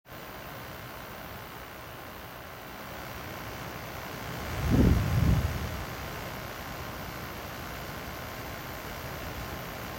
ventizaj.mp3